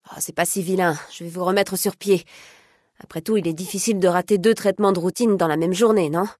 Ada Straus répondant à la demande de soin du joueur en espérant ne pas se rater dans Fallout: New Vegas.
Dialogue audio de Fallout: New Vegas